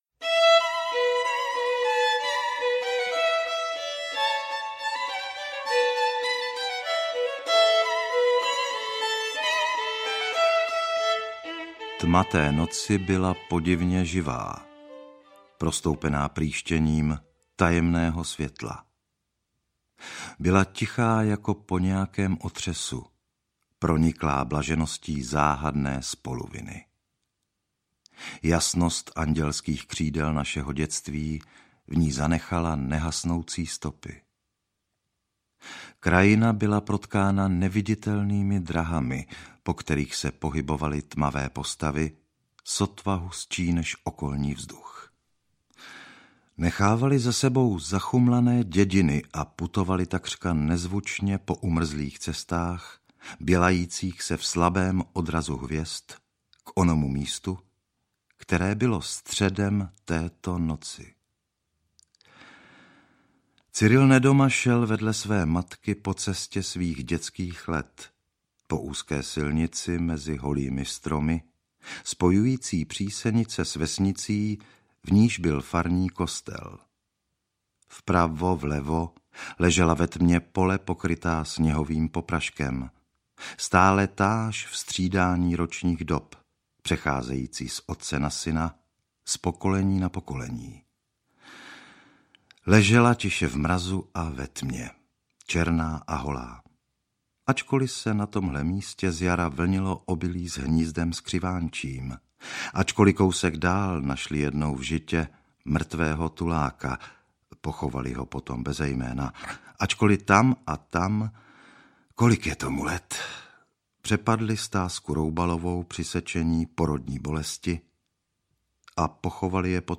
Cesta na jitřní a jiné prózy audiokniha
Ukázka z knihy